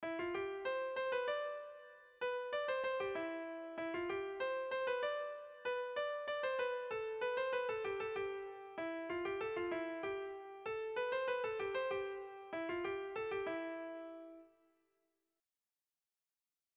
Zortziko txikia (hg) / Lau puntuko txikia (ip)
A1A2BD